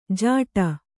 ♪ jāṭa